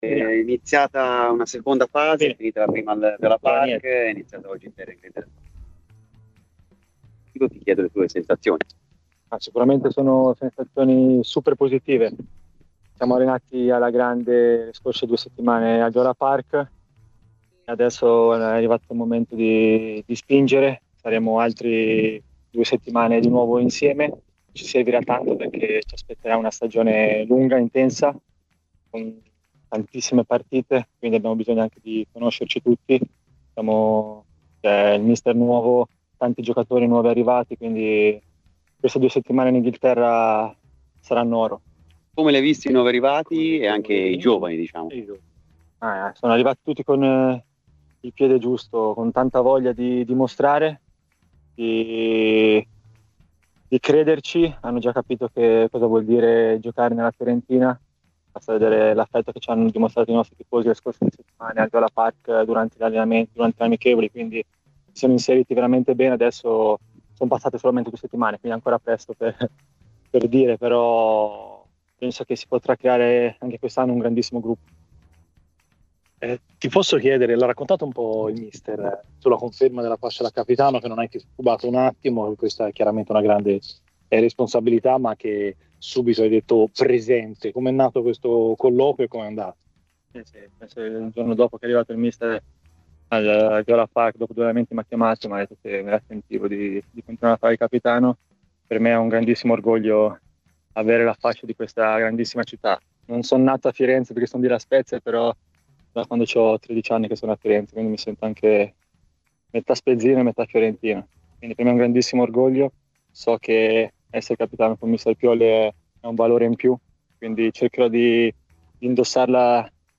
Al termine dell'allenamento odierno della Fiorentina, Luca Ranieri ha parlato così ai media presenti, tra cui Radio FirenzeViola, della situazione in casa viola "Sensazioni positive, ci siamo allenati alla grande al Viola Park.